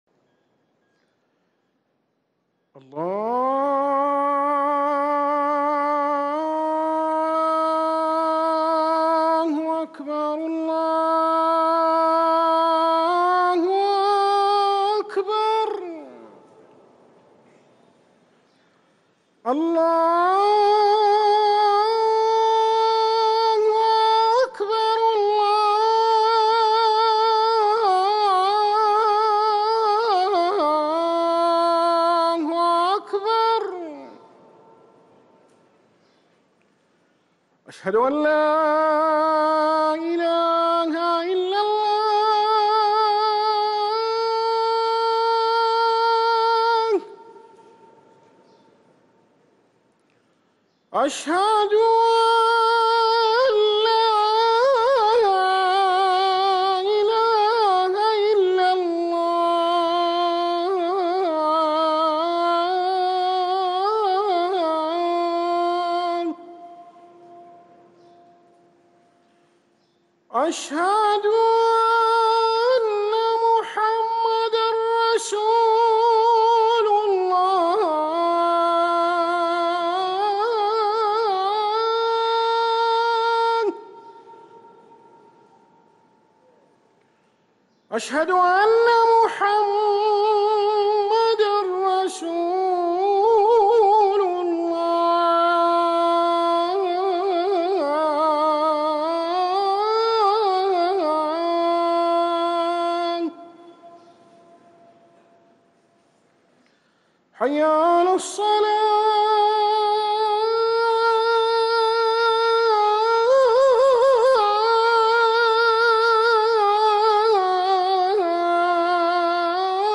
اذان المغرب